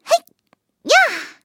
卡尔臼炮开火语音1.OGG